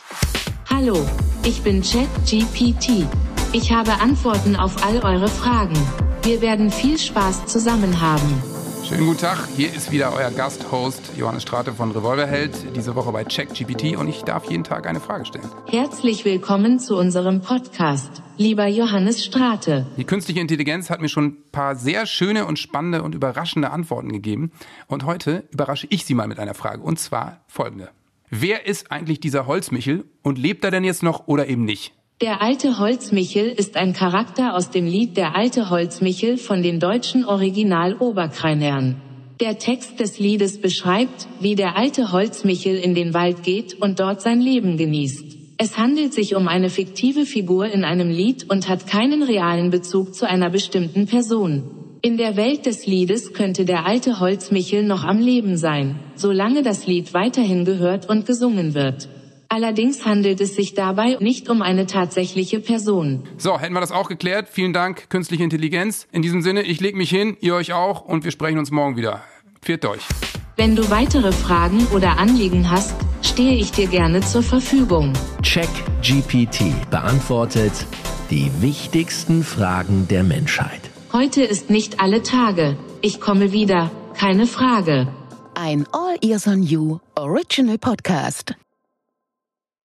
Johannes Strate & KI